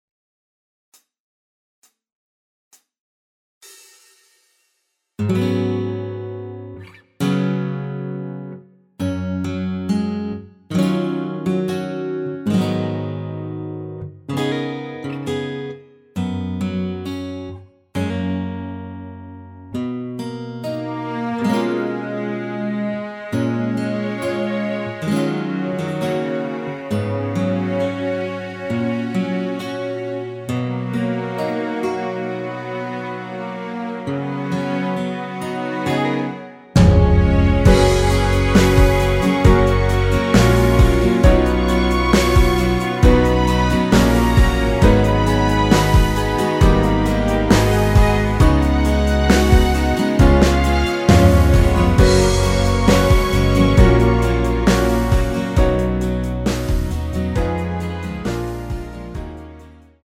전주 없이 시작 하는곡이라 카운트 넣어 놓았습니다.(미리듣기 참조)
F#
앞부분30초, 뒷부분30초씩 편집해서 올려 드리고 있습니다.
중간에 음이 끈어지고 다시 나오는 이유는